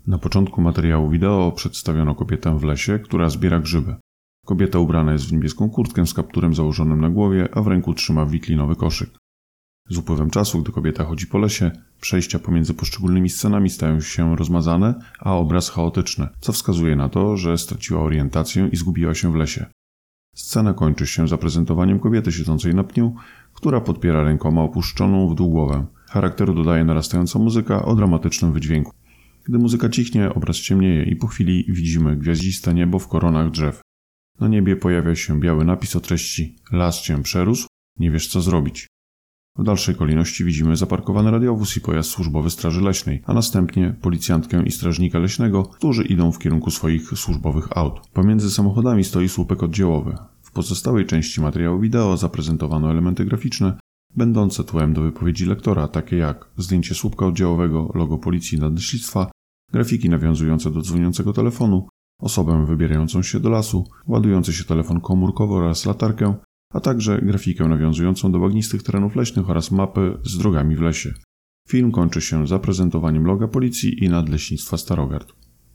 Nagranie audio Audio_deskrypcja_do_materialu_wideo.mp3